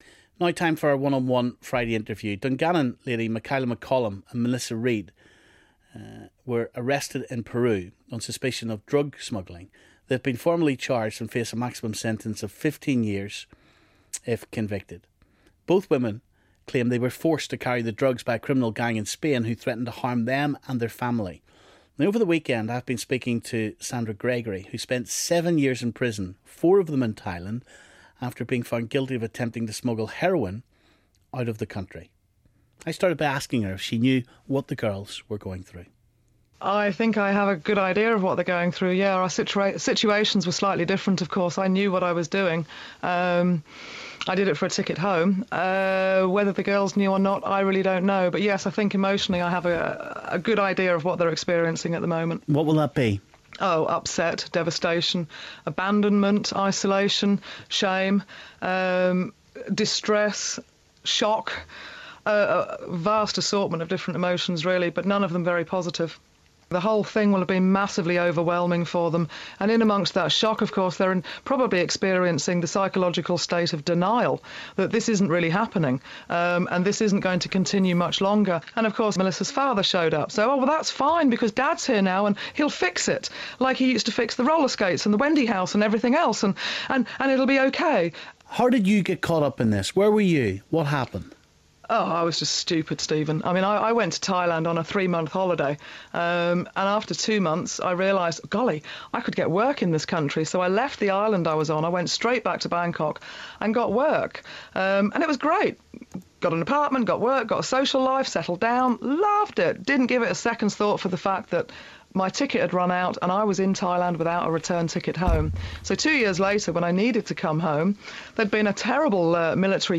Big Friday Interview